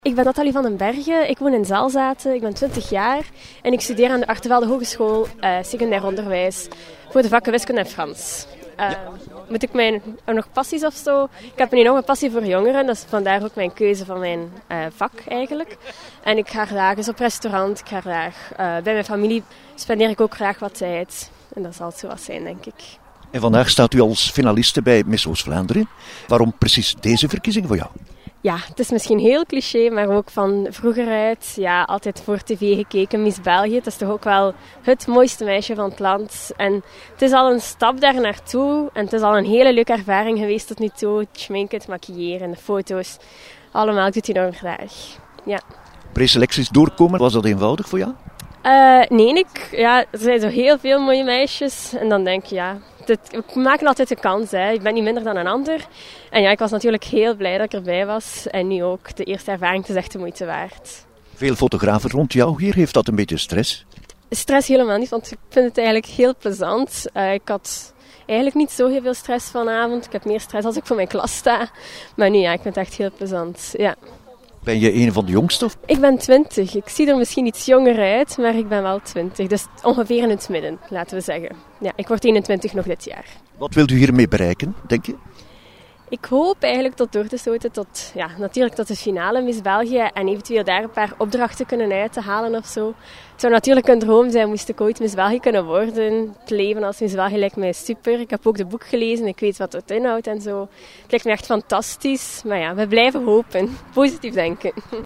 We gingen in mei ,bij de voorstelling van de kandidaten in Oudenaarde , al eens kijken wat voor moois ze in de aanbieding
voor de interviews :